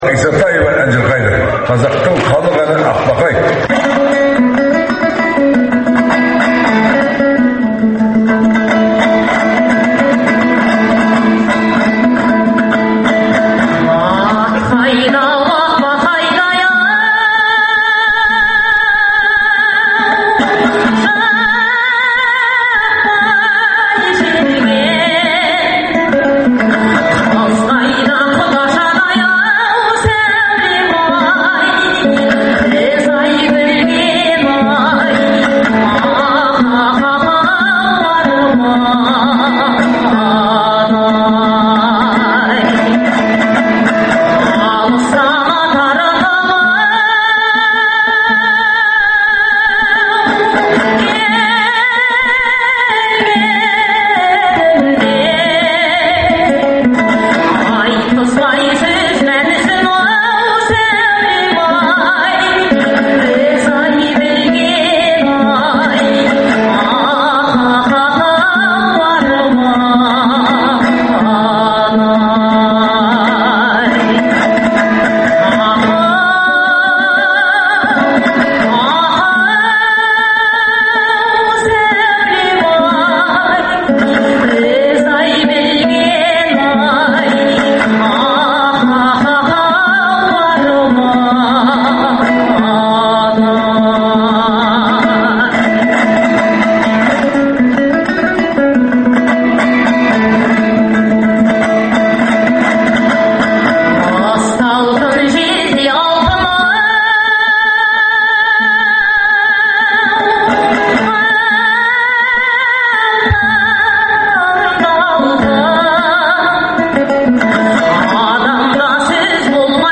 Айтыс - Азаттықта
Айтыстан үзінділер беріледі, ақындар айтысының үздік нұсқалары тыңдарменға сол қалпында ұсынылып отырады.